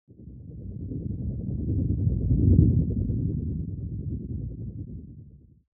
دانلود آهنگ طوفان 27 از افکت صوتی طبیعت و محیط
جلوه های صوتی
دانلود صدای طوفان 27 از ساعد نیوز با لینک مستقیم و کیفیت بالا